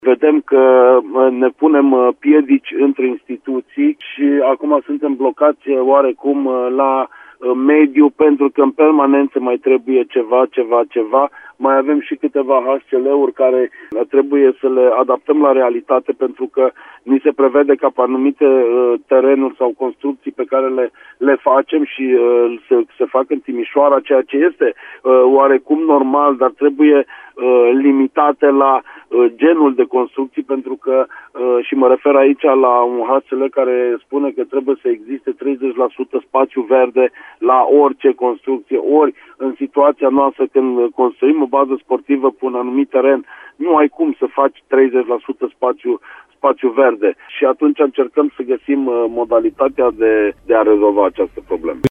Baza Sportivă Tip 1, pe care Compania Națională de Investiții o va ridica pe strada Costică Rădulescu, așteaptă în continuare avizul de mediu. În plus, conform unor hotărâri mai vechi ale Consiliului Local, în proiect trebuie inclusă și o anumită proporție de spațiu verde, a declarat, la Radio Timișoara, viceprimarul Cosmin Tabără: